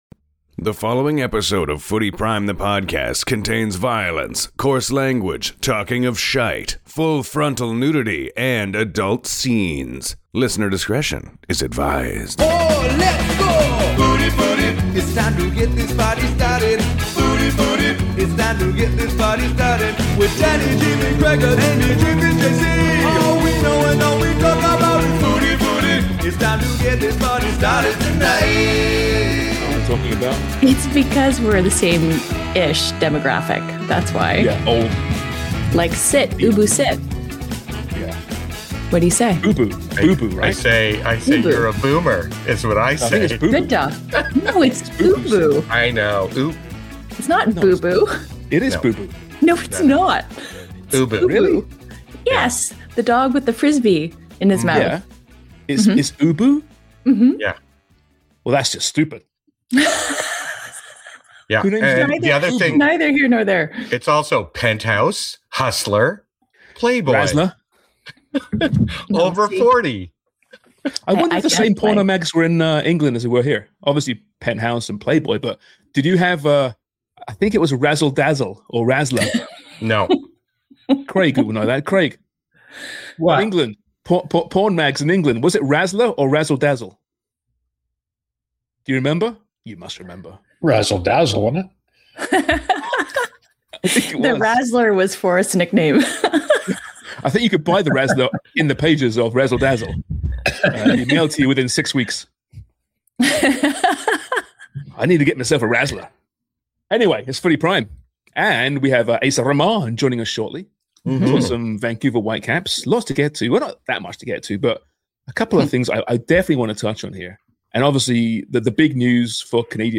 But first they cover, CANMNT and Bayern Munich's Alphonso Davies injury and the UEFA Champions League with Tottenham flailing. Oh and some delightful laughter for free.